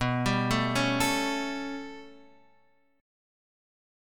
B7sus2#5 chord